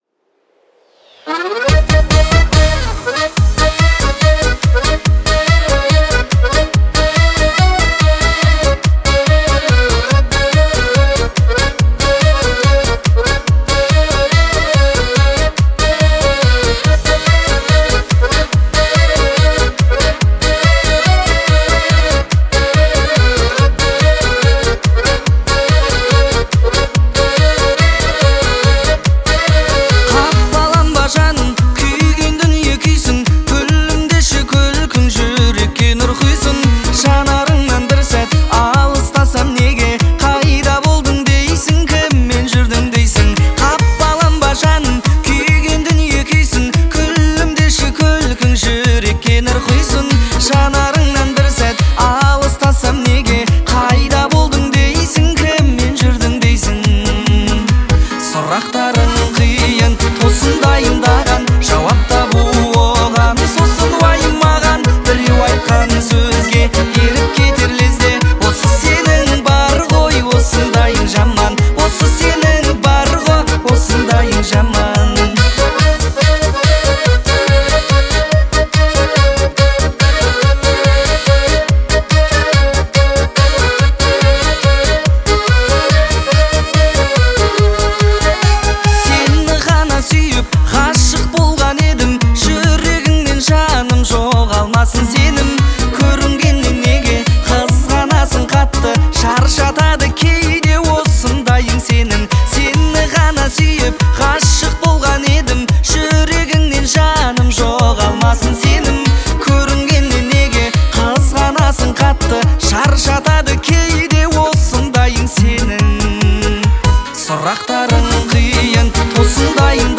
это эмоциональная песня в жанре казахского поп-фолка
мастерски передает эмоции через свой вокал